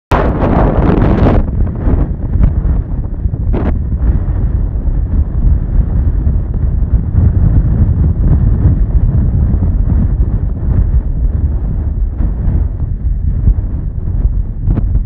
Tempo doido, um vento terrível sound effects free download
Tempo doido, um vento terrível em SC!